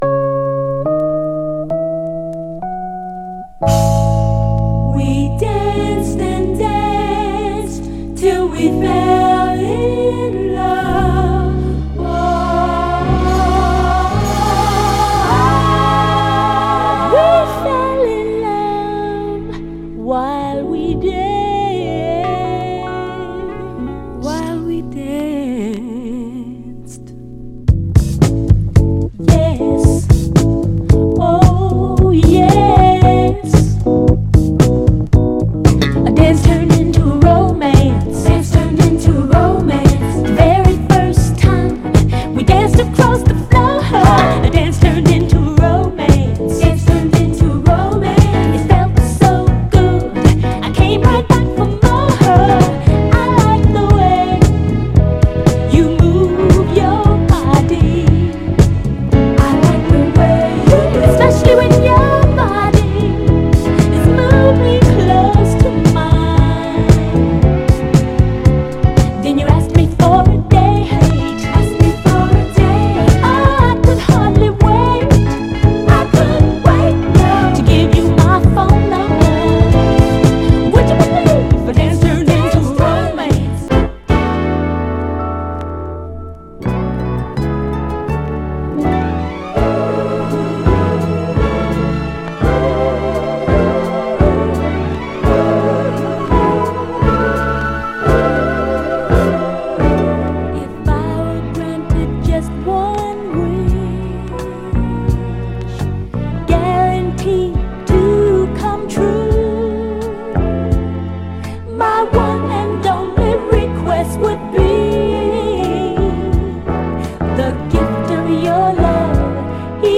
メロウ・ブギー/モダン・ソウルの名作です！
盤はB面エッジに一部目立つスレ、小キズ箇所あり、序盤で僅かにプチッと鳴りますが気にならない程度かと。
※試聴音源は実際にお送りする商品から録音したものです※